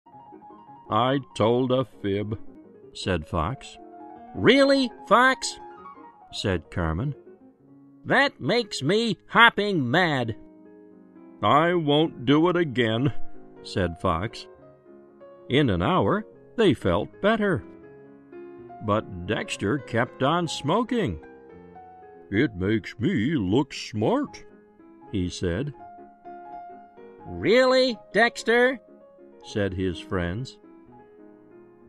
在线英语听力室小狐外传 第23期:撒谎的听力文件下载,《小狐外传》是双语有声读物下面的子栏目，非常适合英语学习爱好者进行细心品读。故事内容讲述了一个小男生在学校、家庭里的各种角色转换以及生活中的趣事。